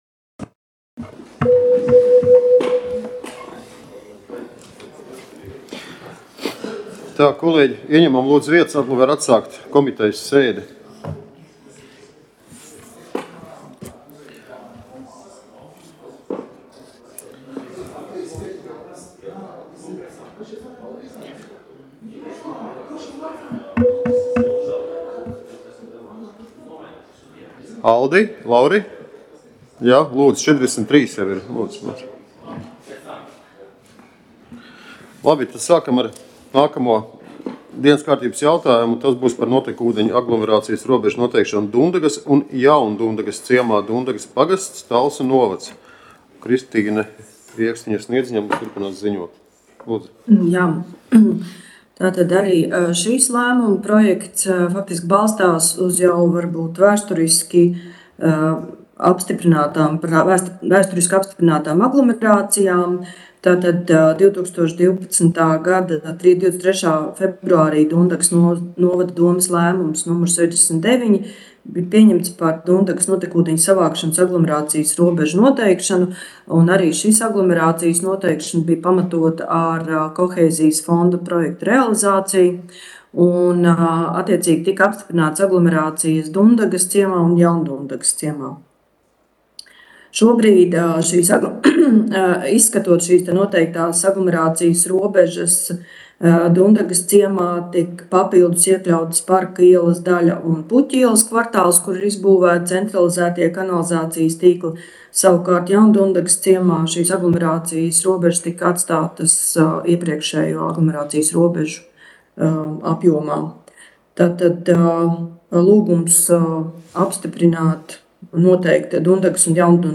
Attīstības komitejas sēde Nr. 12
Komitejas sēdes audio